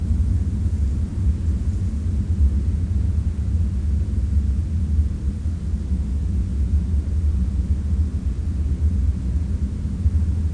umgebung.mp3